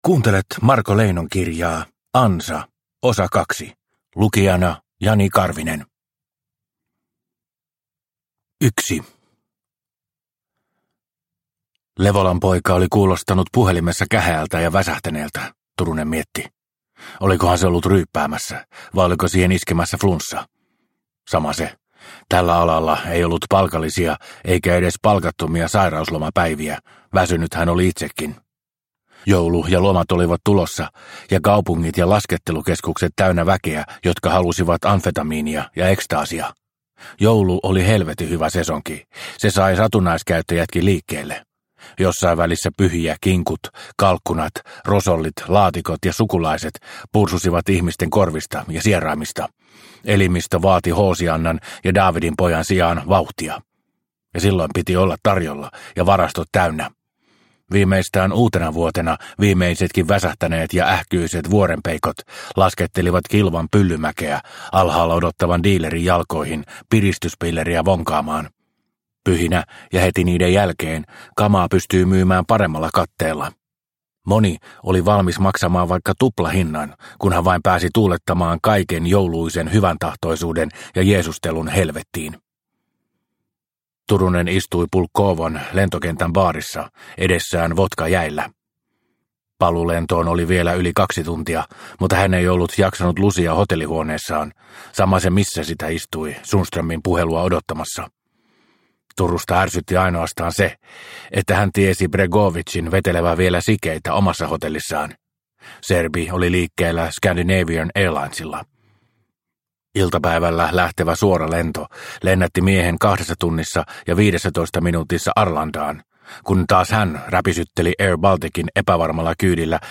Ansa – Ljudbok – Laddas ner